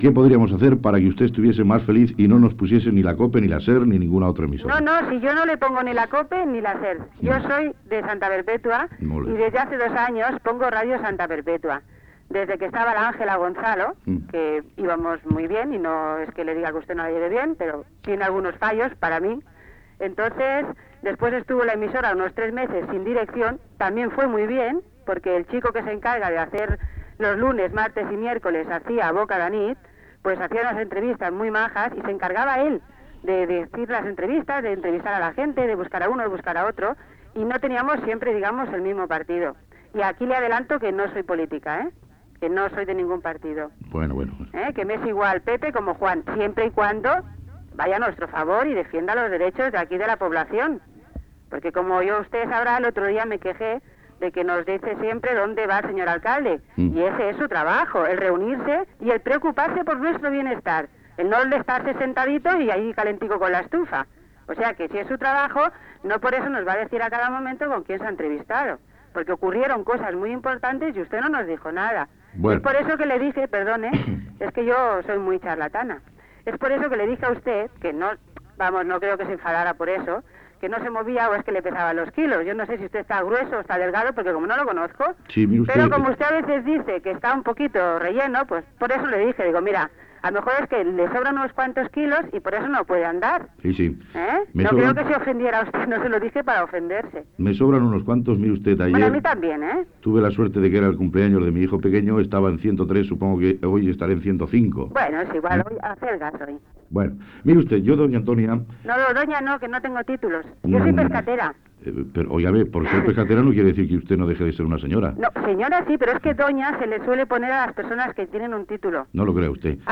Trucada telefònica d'una oïdora
Fragment procedent d'unes bobines magnetofòniques